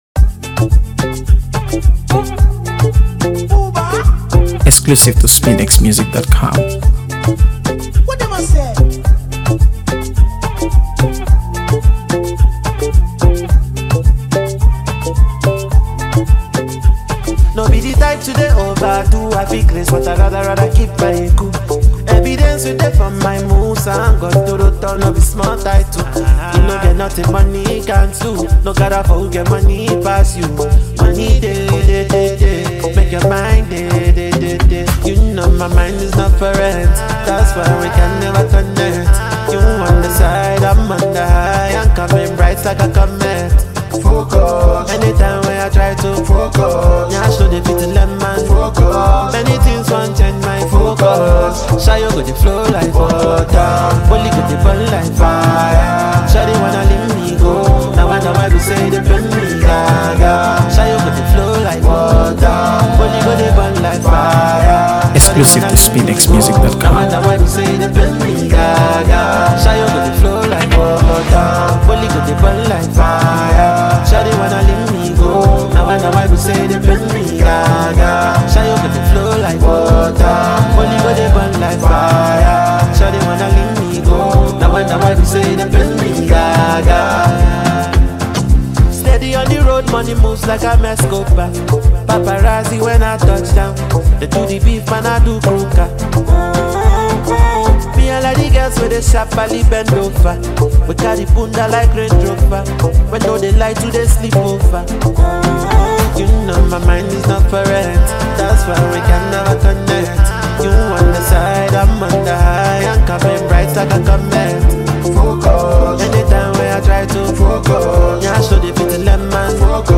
AfroBeats | AfroBeats songs
a track that exudes sensuality and elegance